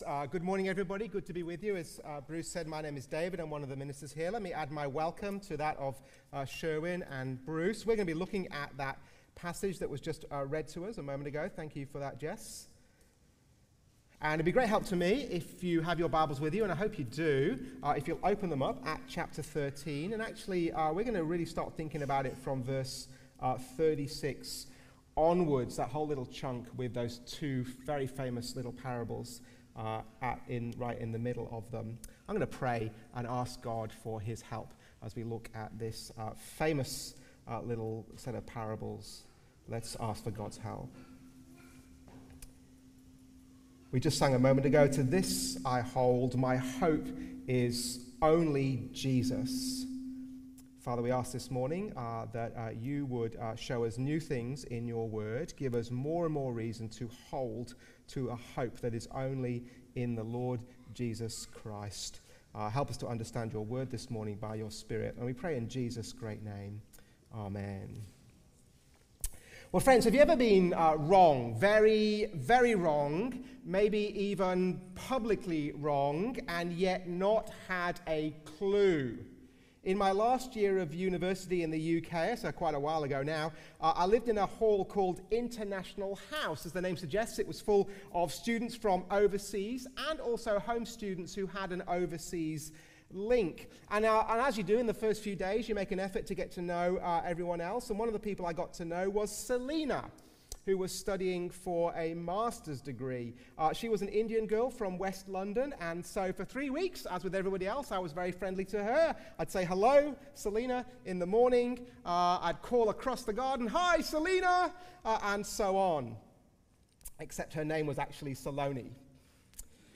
Sermons | St Johns Anglican Cathedral Parramatta